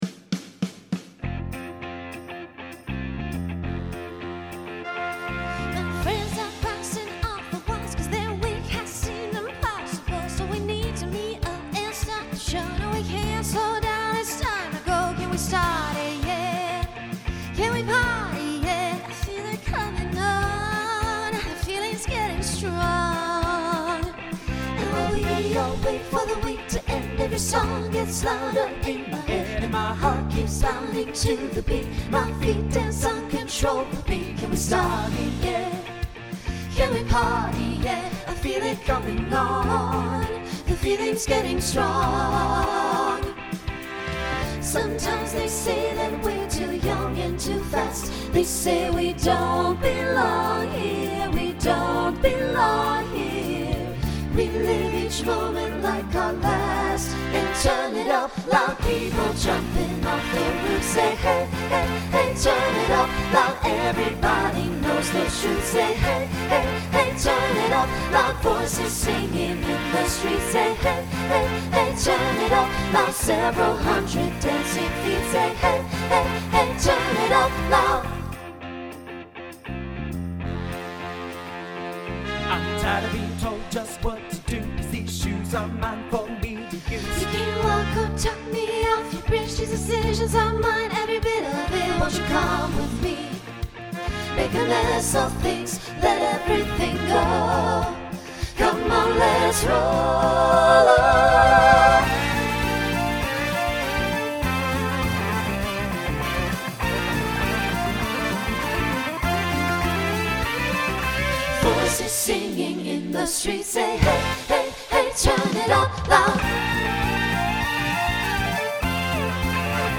Genre Rock Instrumental combo
Opener Voicing SATB